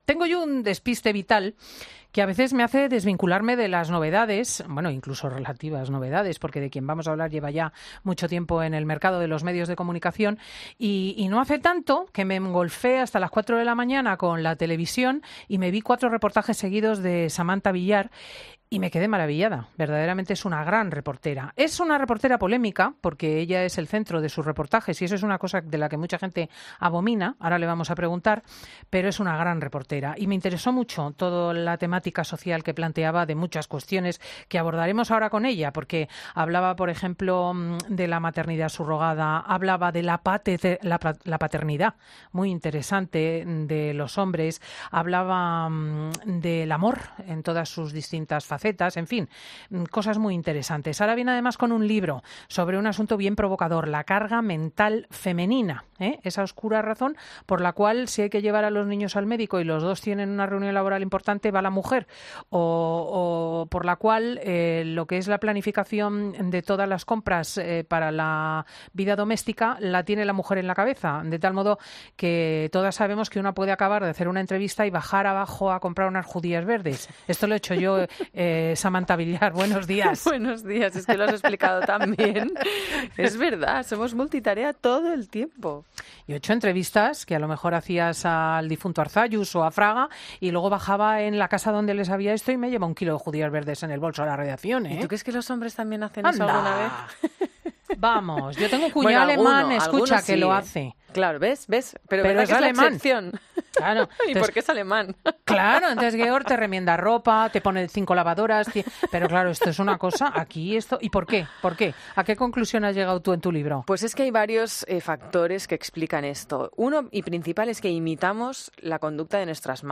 La periodista Samanta Villar ha escrito sobre este fenómeno, en su libro "La carga mental femenina", y ha venido a explicarlo en el 'Fin de Semana' de COPE: “Tenemos dos trabajos.